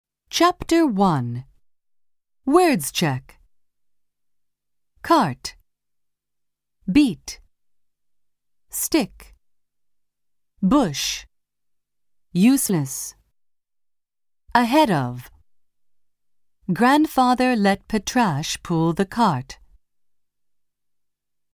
音声には効果音も取り入れていますので、学習者が興味を失わずに最後まで聴き続けることができます。